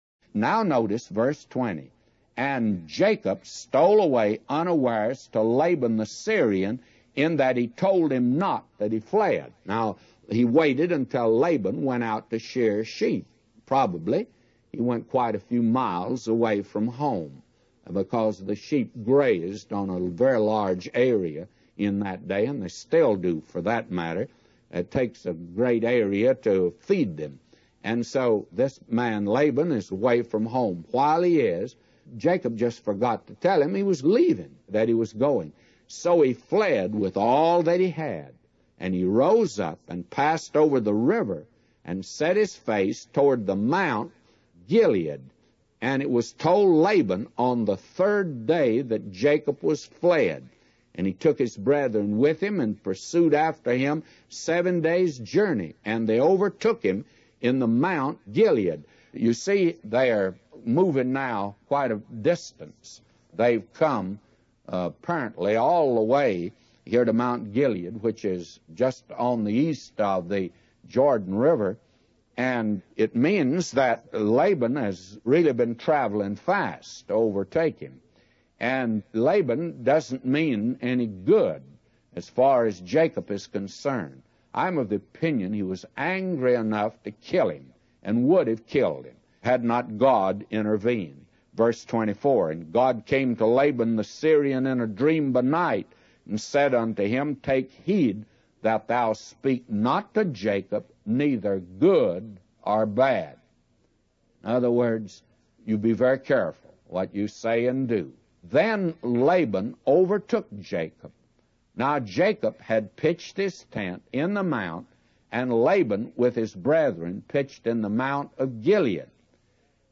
A Commentary By J Vernon MCgee For Genesis 31:20-999